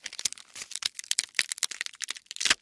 Звуки чеснока - скачать и слушать онлайн бесплатно в mp3